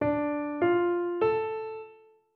Rozpoznawanie trybu melodii (smutna,wesoła)